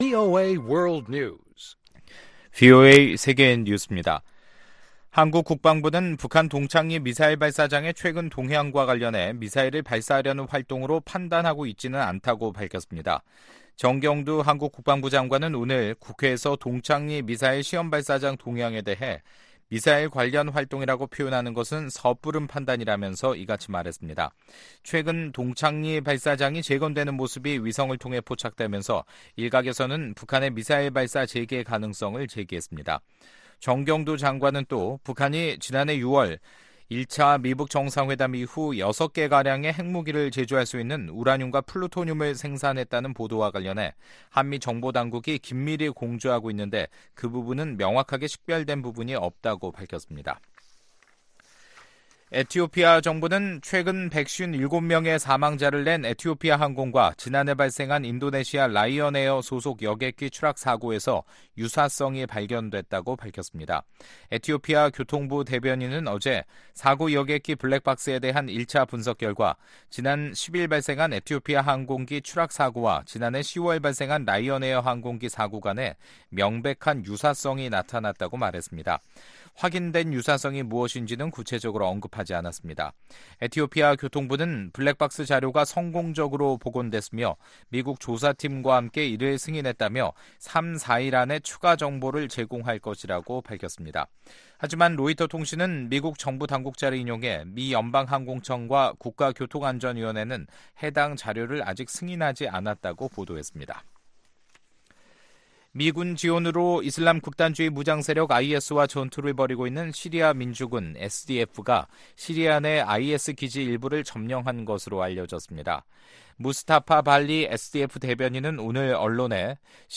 VOA 한국어 간판 뉴스 프로그램 '뉴스 투데이', 2019년 3월 18일 3부 방송입니다. 북한이 핵-미사일 실험을 재개하는 것은 좋은 생각이 아니며, 트럼프 대통령은 협상으로 문제 해결을 바란다고 존 볼튼 백악관 국가안보보좌관이 밝혔습니다. 미국 국무부는 현재로선 북한에 대한 제재를 완화하거나 해체할 계획이 전혀 없음을 분명히 했습니다.